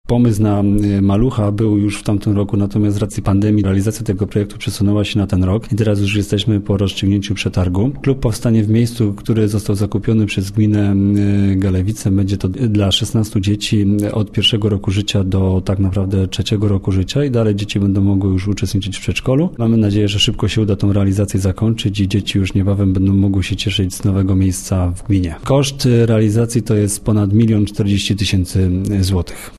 – mówił wójt, Piotr Kołodziej.